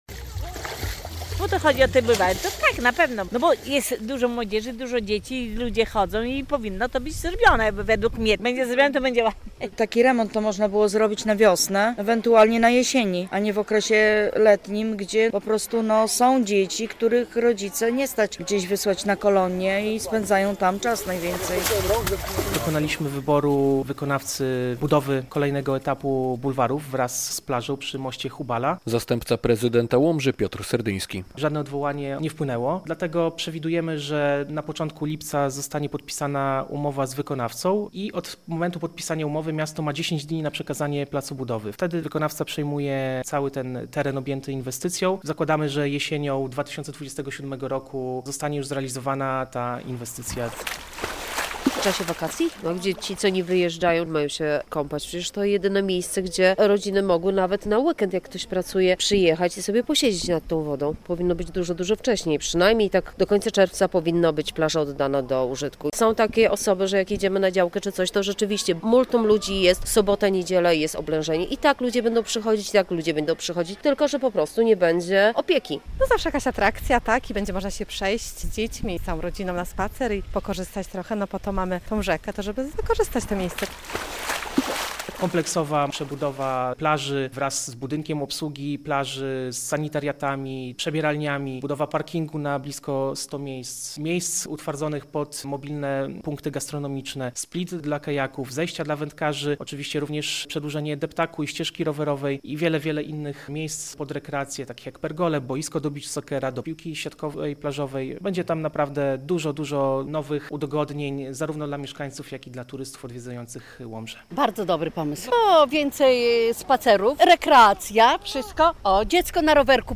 Taki remont można było zrobić na wiosnę, ewentualnie na jesieni, a nie teraz w okresie letnim, gdy są dzieci, których rodziców nie stać na kolonie i spędzają tam czas najczęściej - mówi jedna z mieszkanek Łomży.
Bardzo dobry pomysł, a że jest okres letni, gdzie indziej można jechać - mówi inna.